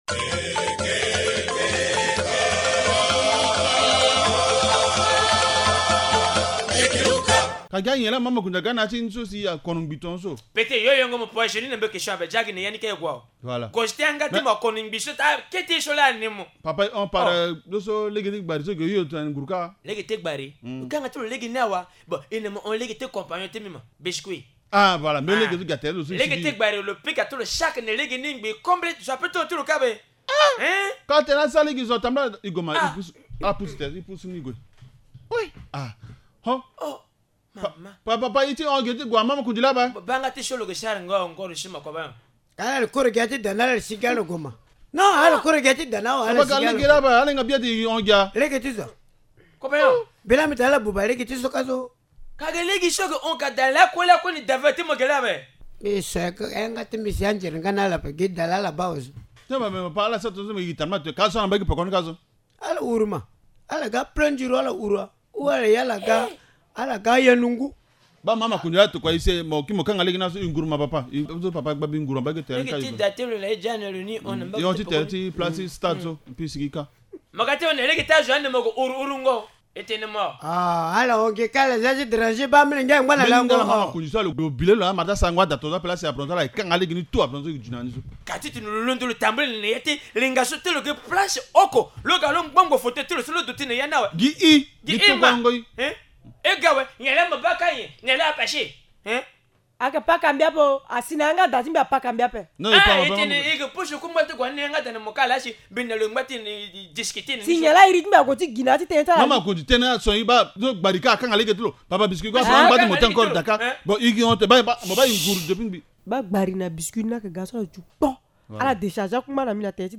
Linga théâtre : les rues du village sont obstruées par les occupants